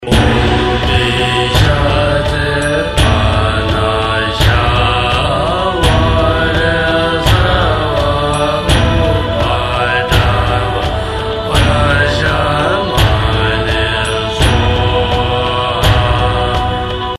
葉衣佛母心咒(莊嚴版)